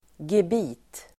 Uttal: [geb'i:t]